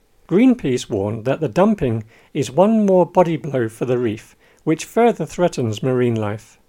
DICTATION 9